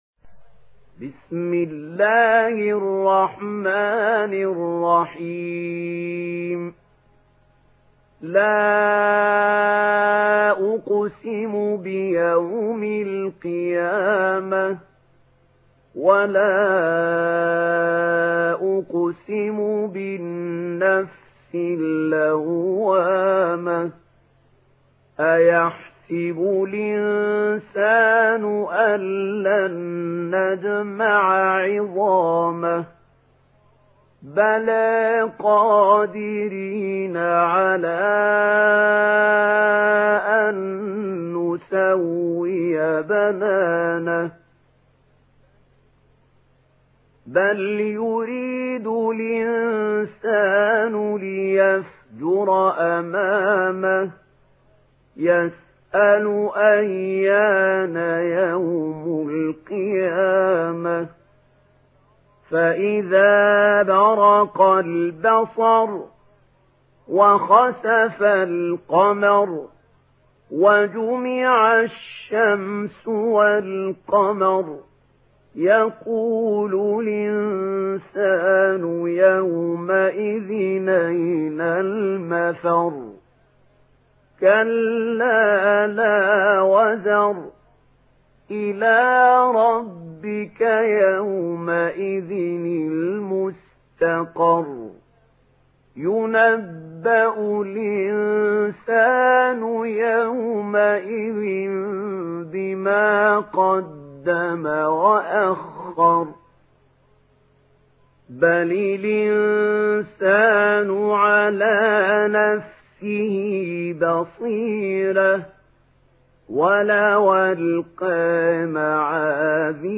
Surah Al-Qiyamah MP3 by Mahmoud Khalil Al-Hussary in Warsh An Nafi narration.
Murattal Warsh An Nafi